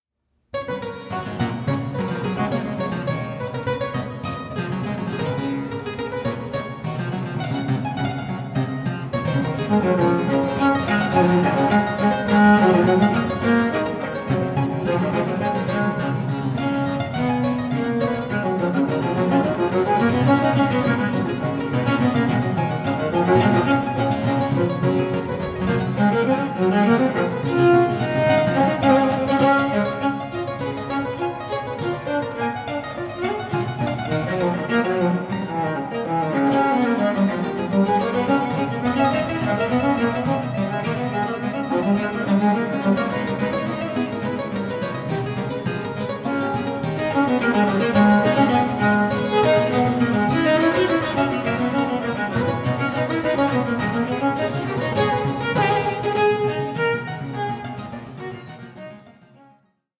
five-string cello piccolo
clavichord